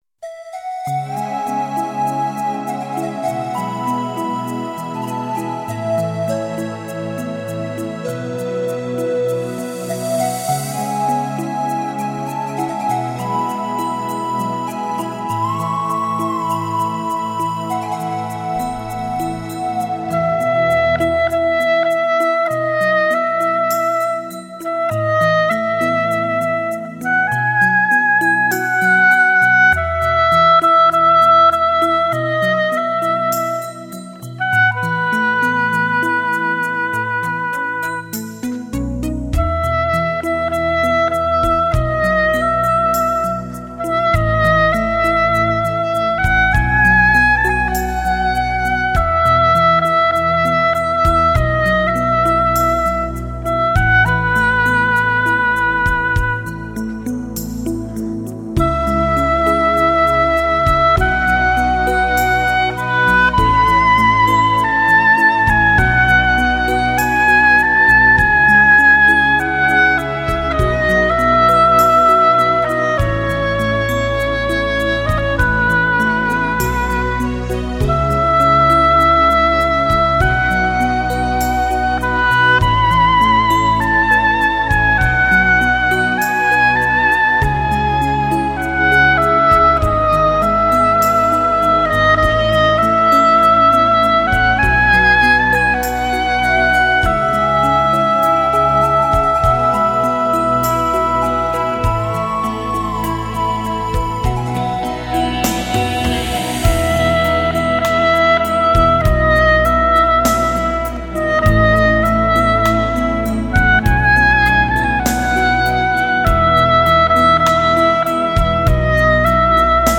这些歌曲旋律优美，歌词真挚，用双簧管演奏，将带给你一种似曾相识、却又不同一般的感觉。